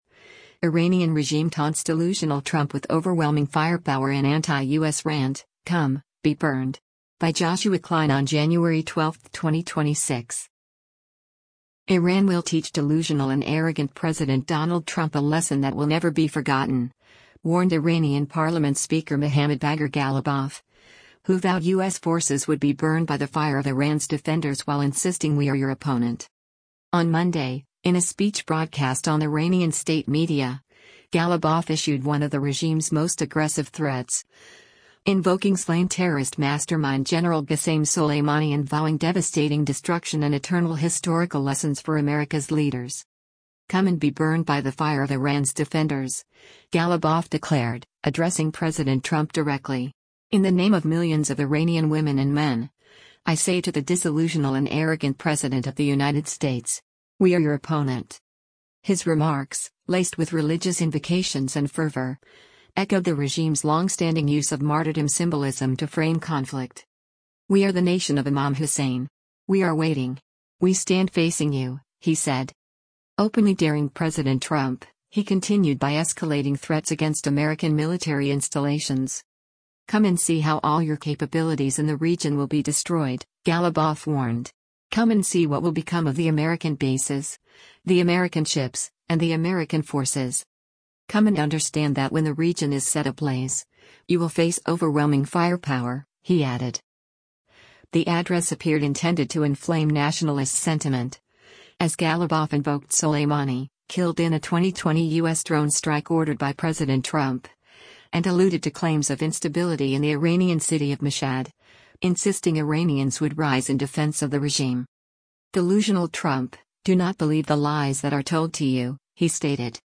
On Monday, in a speech broadcast on Iranian state media, Ghalibaf issued one of the regime’s most aggressive threats, invoking slain terrorist mastermind Gen. Qasem Soleimani and vowing devastating destruction and “eternal historical lessons” for America’s leaders.
His remarks, laced with religious invocations and fervor, echoed the regime’s long-standing use of martyrdom symbolism to frame conflict.